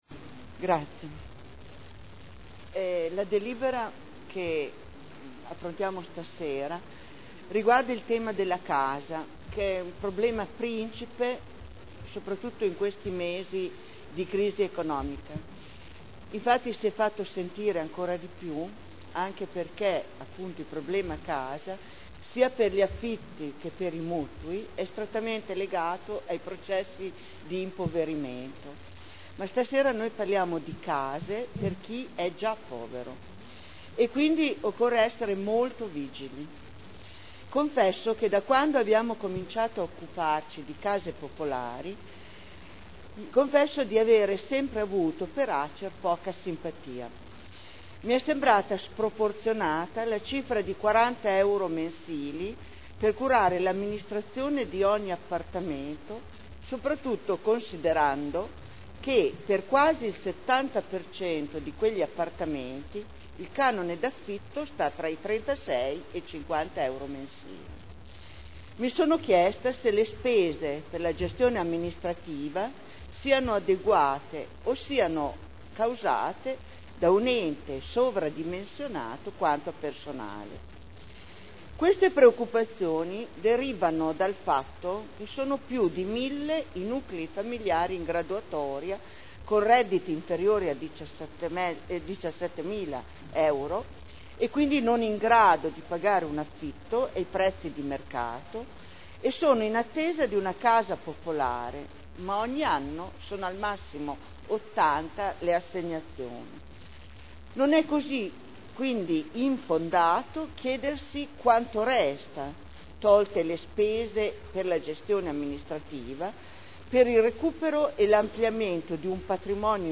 Dibattito su delibera: Affidamento in concessione all’Azienda Casa Emilia Romagna del servizio di gestione del patrimonio di Edilizia Residenziale pubblica di proprietà del Comune – Approvazione Accordo Quadro provinciale e Contratto di Servizio del Comune di Modena (Commissione consiliare del 7 dicembre 2010)